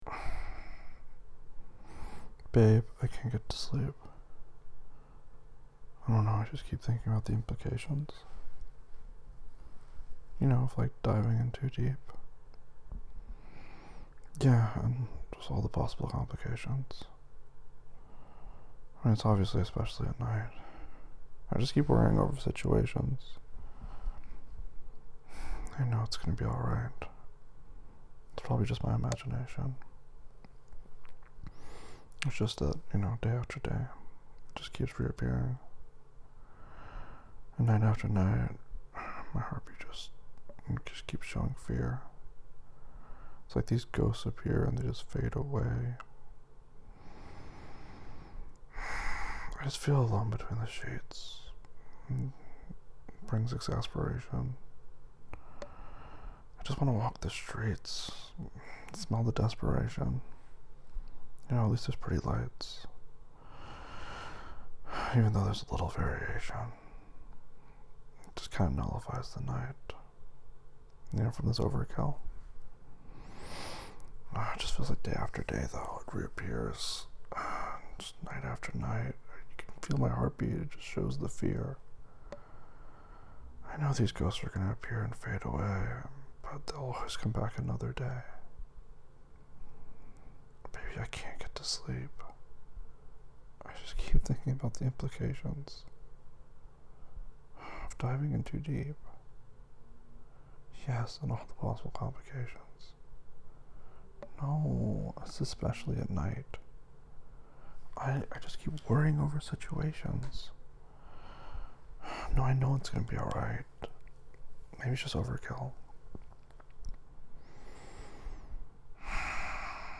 That’s exactly what they are he’s just reading it